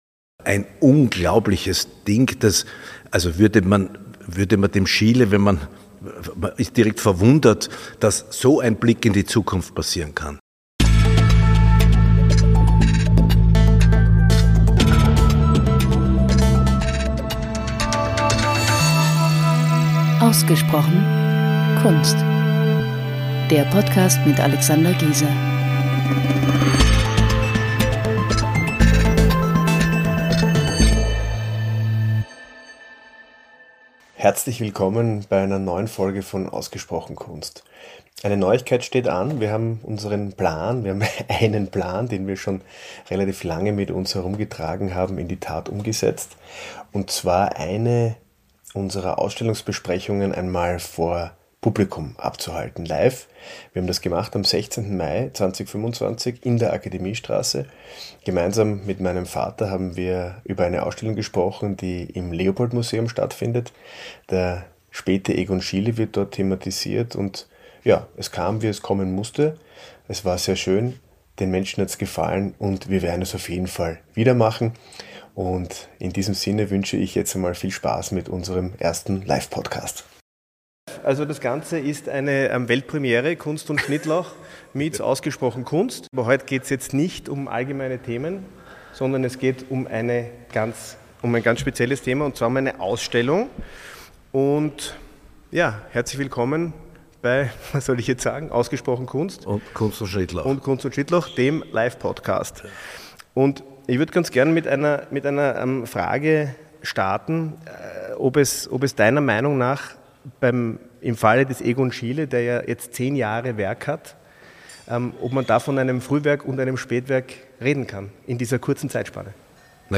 Live vor Publikum eine Ausstellung zu besprechen, macht eindeutig mehr Spaß, als das Gespräch im stillen Kämmerchen abzuhalten.